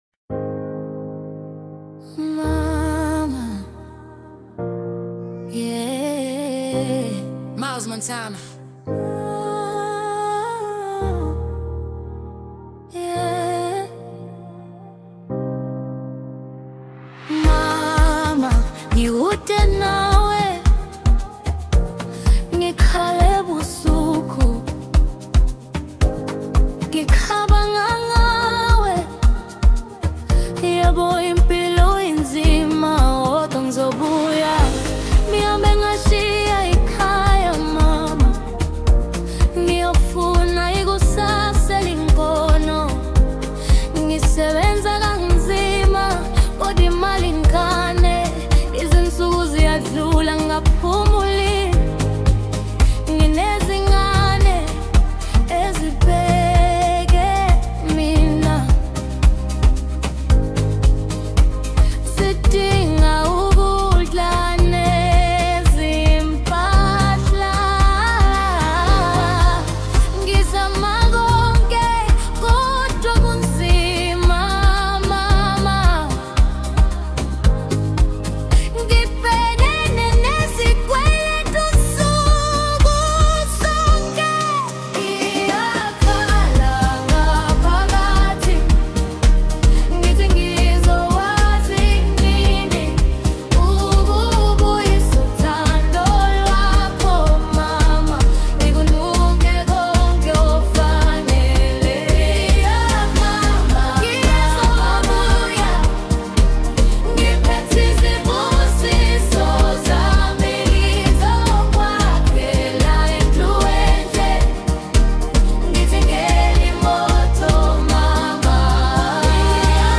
Amapiano, Lekompo